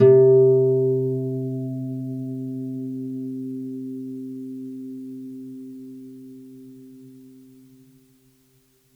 KSHarp_C3_mf.wav